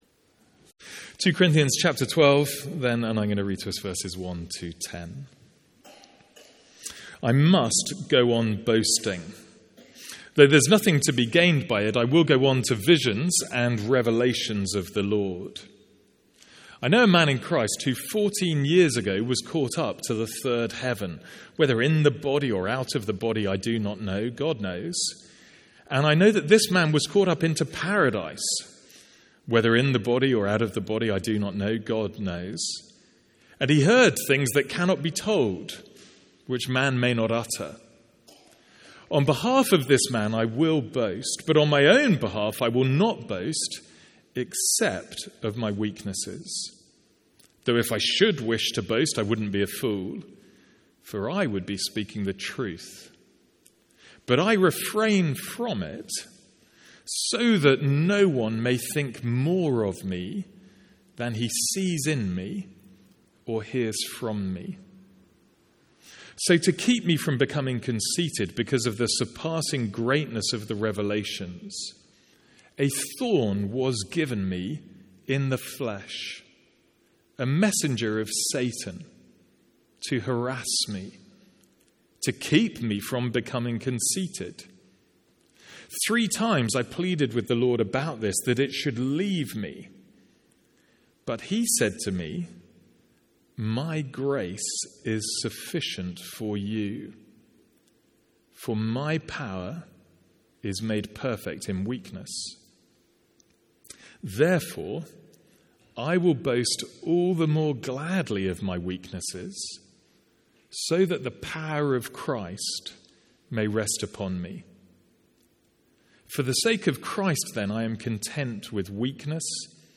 Sermons | St Andrews Free Church
From our evening series in 2 Corinthians.